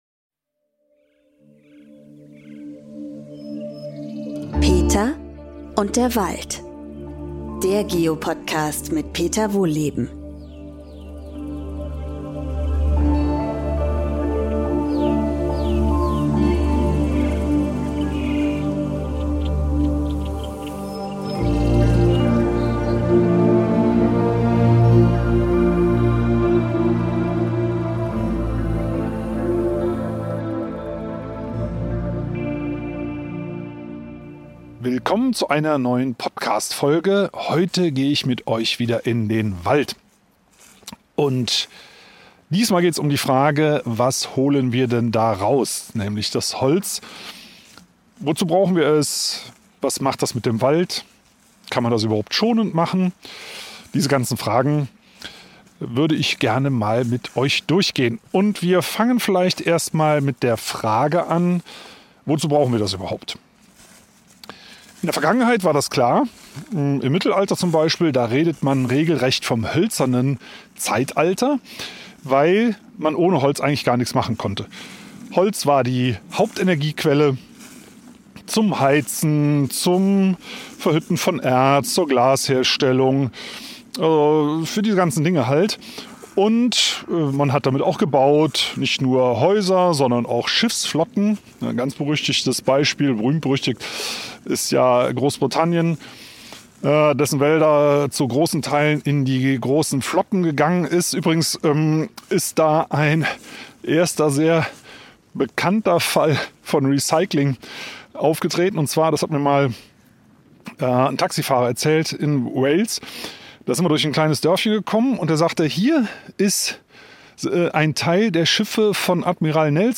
Und wieder geht es mit Peter Wohlleben in den Buchenwald.